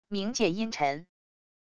冥界阴沉wav音频